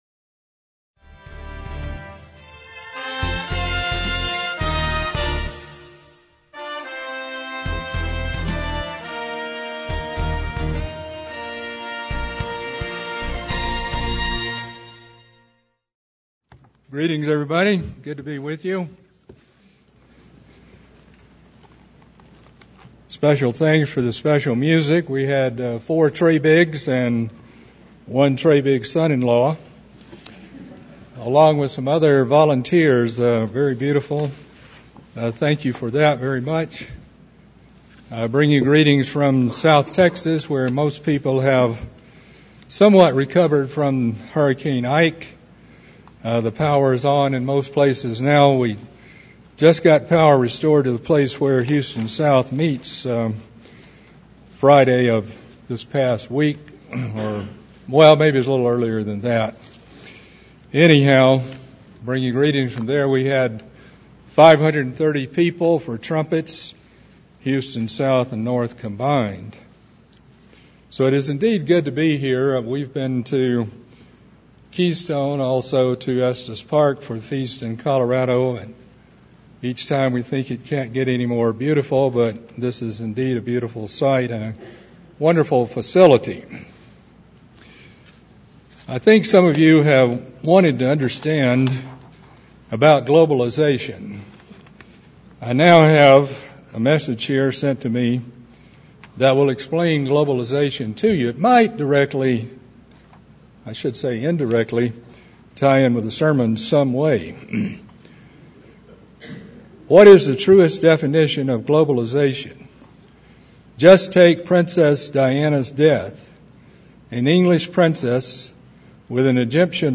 This sermon was given at the Steamboat Springs, Colorado 2008 Feast site.